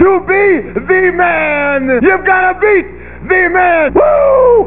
Ric Flair Sound bite
"Wooooooooooo................................."I don't even watch wrestling and I know that.